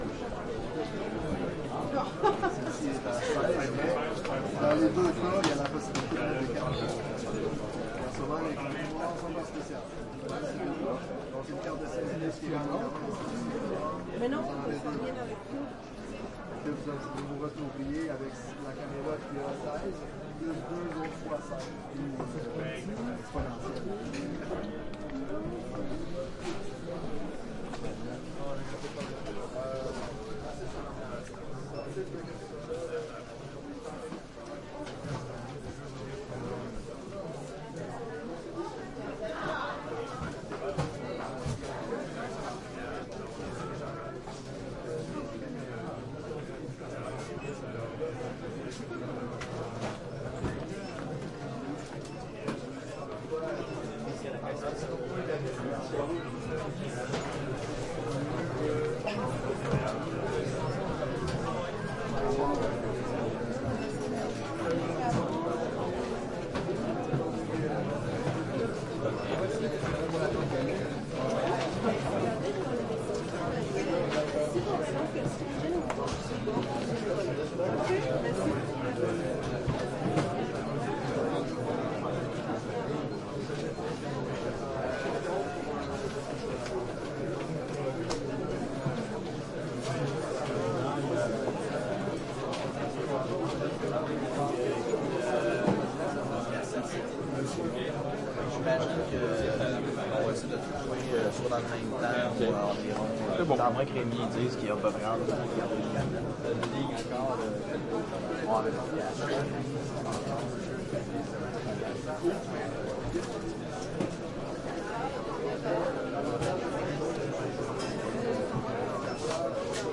蒙特利尔 " CBC加拿大广播电台RDS新闻室更安静的角落1电脑打字的人走过地毯魁北克人的声音蒙特利尔，迦纳
描述：CBC加拿大广播电台RDS新闻室更安静的角落1电脑打字的人走过地毯魁北克声音加拿大蒙特利尔.flac
标签： 蒙特利尔 魁北克 分型 收音机 声音 计算机 新闻编辑室 RDS 更安静 CBC 加拿大
声道立体声